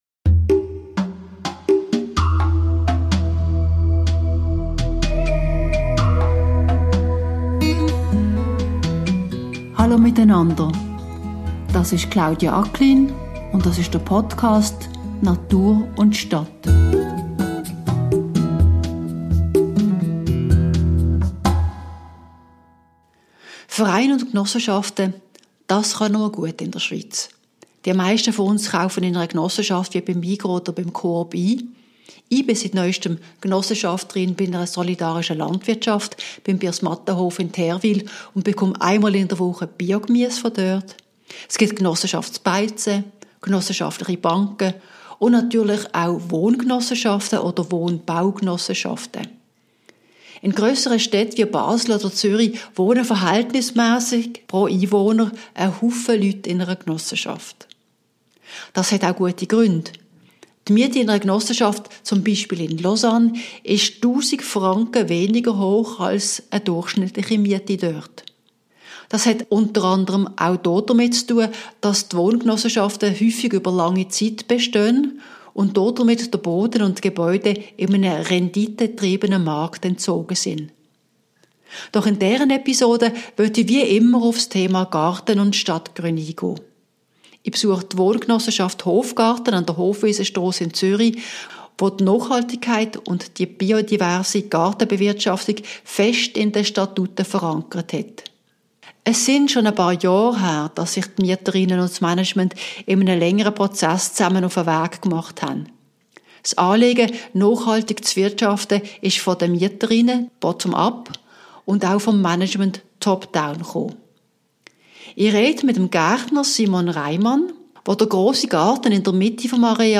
- In dieser Episode besuche ich den Hofgarten an der Hofwiesenstrasse in Zürich, der Nachhaltigkeit und biodiverse Gartenbewirtschaftung fest in den Statuten verankert hat.